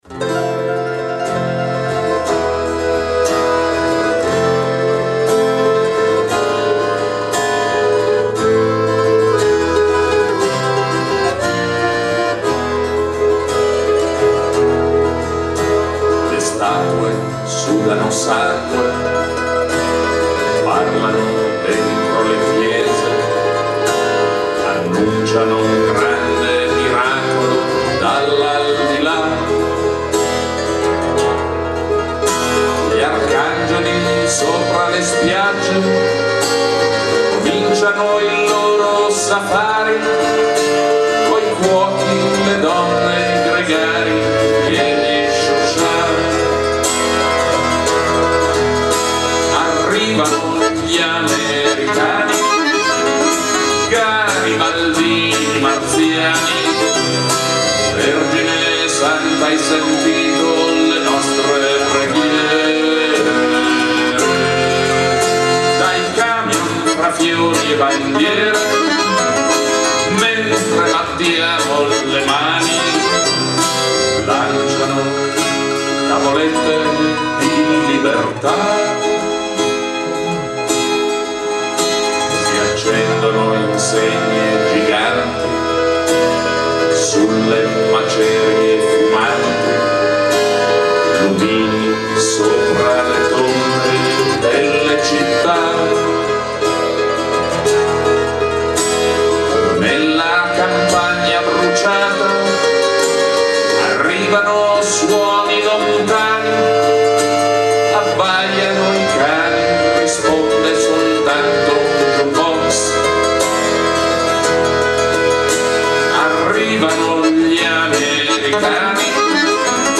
voce e chitarra
bayan) - Dal vivo, Nonantola